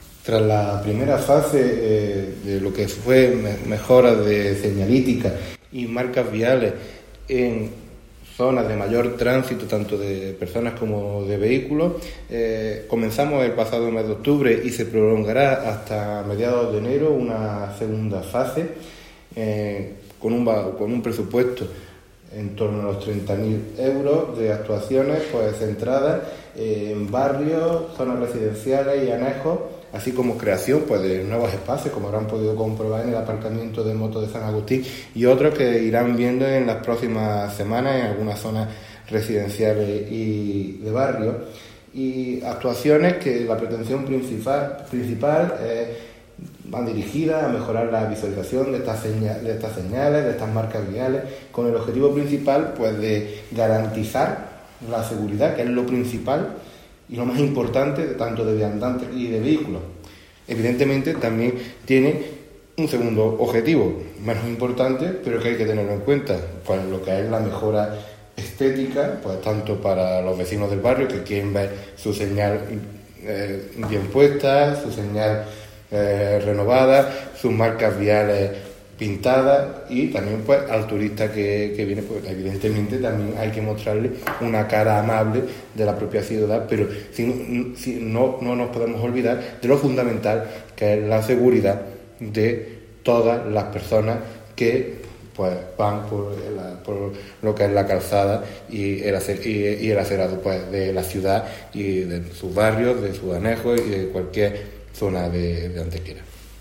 El teniente de alcalde delegado de Seguridad y Tráfico, Antonio García Mendoza, informa del desarrollo en la actualidad de la segunda fase del Plan de Mejora de Señalética y Marcas Viales que promueve el Ayuntamiento de Antequera con el firme propósito de mejorar la seguridad vial en las vías públicas del municipio favoreciendo para ello la visibilidad en usuarios de vehículos y viandantes.
Cortes de voz